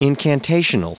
Prononciation du mot incantational en anglais (fichier audio)
incantational.wav